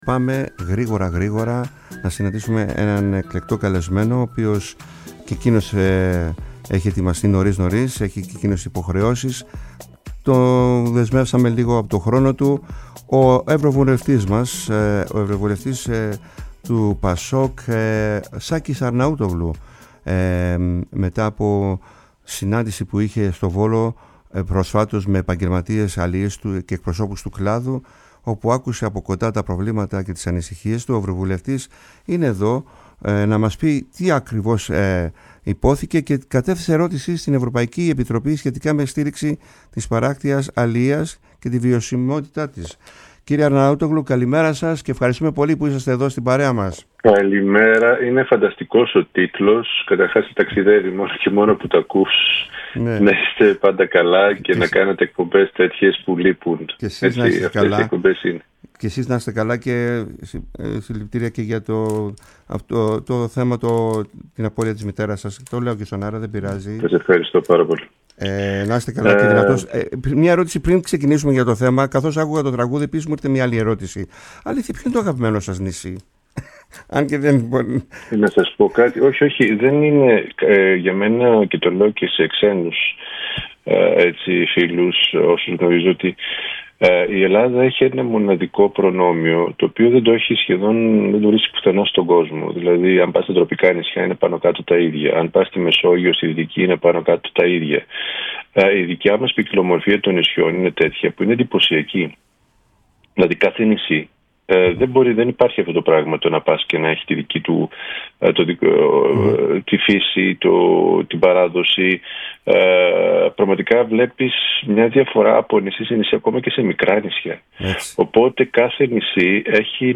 Ο ευρωβουλευτής του ΠΑΣΟΚ Σάκης Αρναούτογλου είναι στο τιμόνι της εκπομπής «ΚΑΛΕΣ ΘΑΛΑΣΣΕΣ» στη ΦΩΝΗ ΤΗΣ ΕΛΛΑΔΑΣ.
Συνεντεύξεις